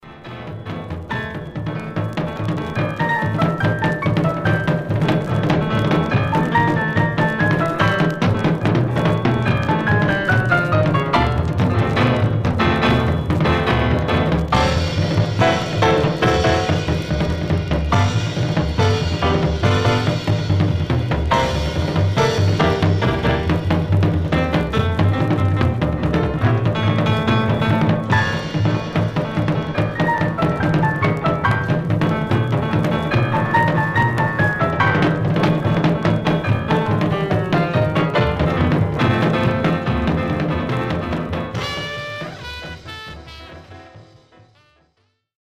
Stereo/mono Mono
Jazz